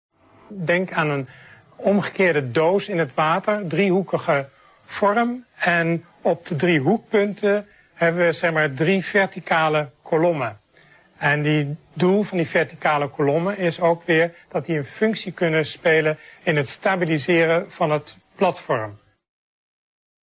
gesproken commentaar